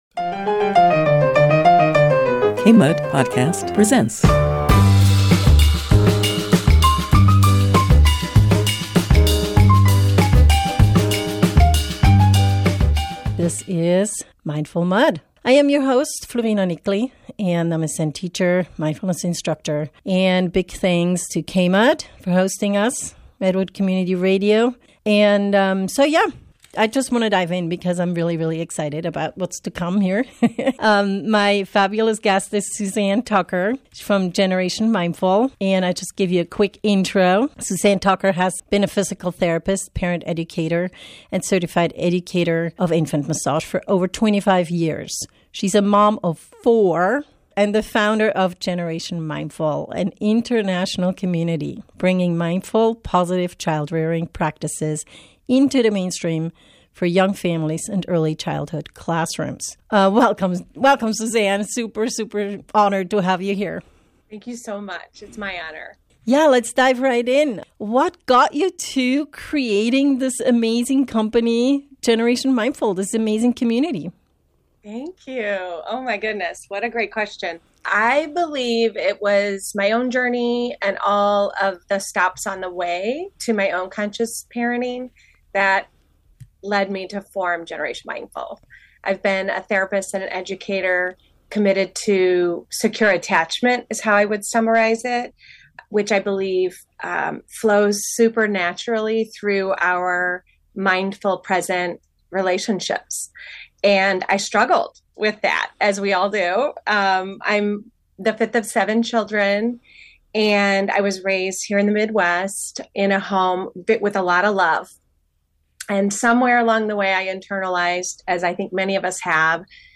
This conversation is full of inspiring stories and tools for you to build a closer relationship with your kids. Learn about the time-in-tool-kit and how teaching children social emotional intelligence is easier than you thought.